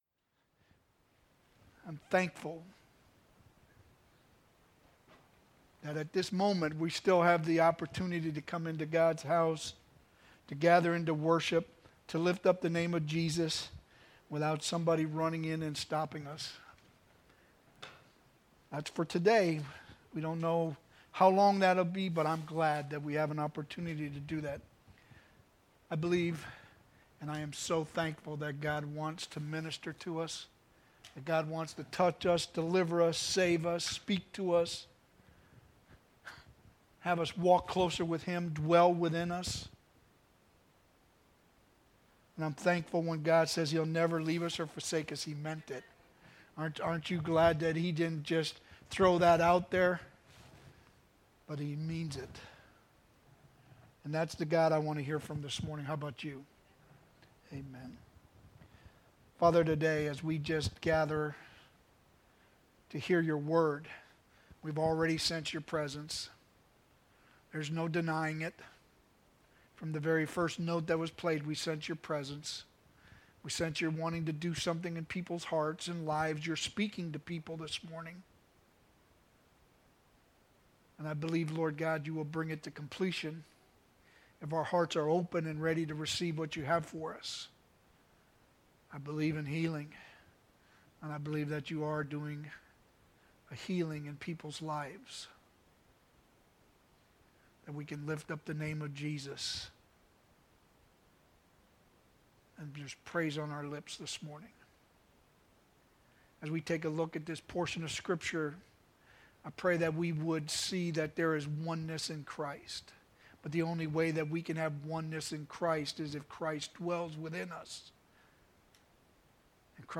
Sermons « Rosedale Church of the Nazarene